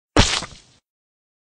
splat.ogg